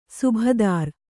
♪ subhadār